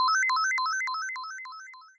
Emitter: Hearts: Sound effect